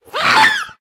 Minecraft Version Minecraft Version snapshot Latest Release | Latest Snapshot snapshot / assets / minecraft / sounds / mob / ghast / scream3.ogg Compare With Compare With Latest Release | Latest Snapshot
scream3.ogg